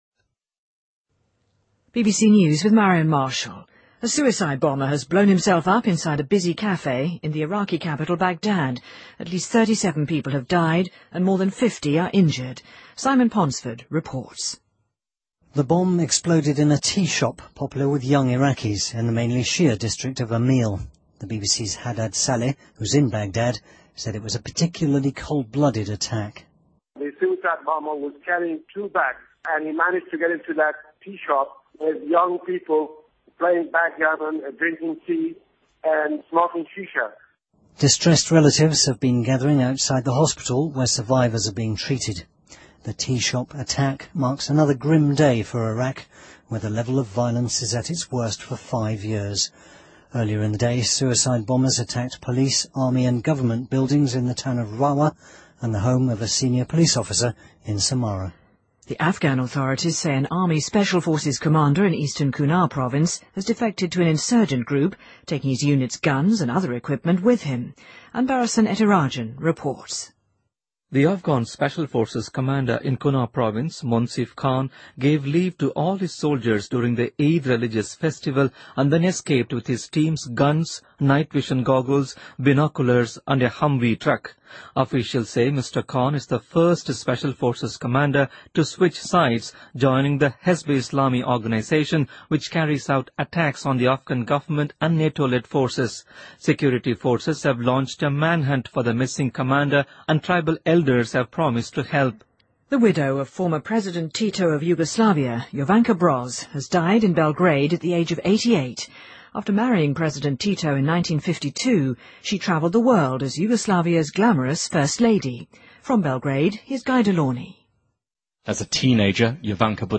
BBC news,南斯拉夫前总统铁托遗孀去世